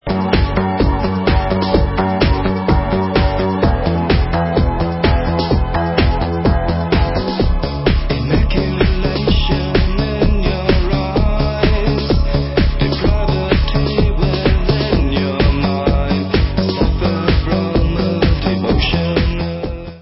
Synthie-pop